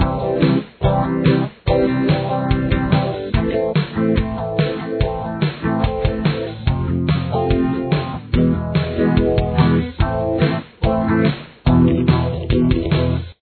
Tempo: 143 beats per minute
Key Signature: A minor
Rhythm Guitar